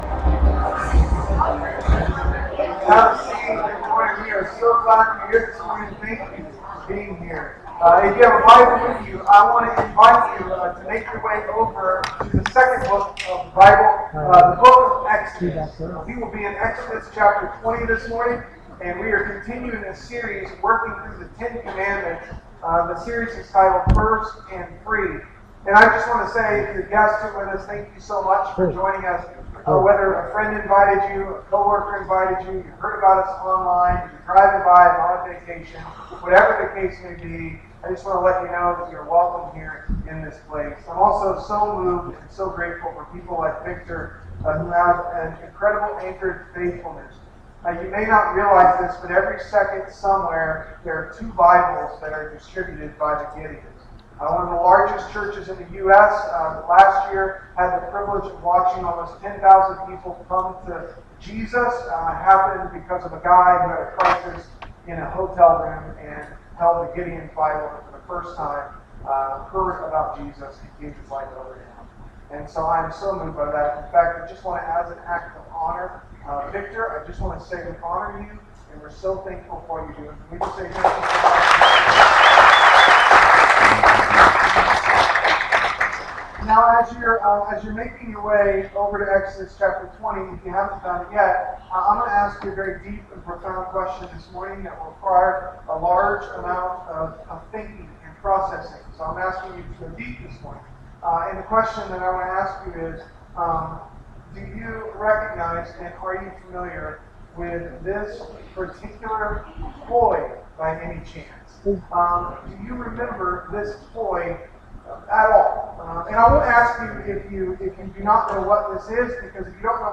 ***We apologize for technical difficulties during this recording.